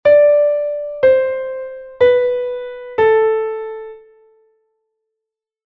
Pitágoras e o seu grupo de colegas crearon un sistema musical baseado no tetracordio: 4 notas formado por 2 tons e 1 semitón sempre descendentes.
re-do-si-la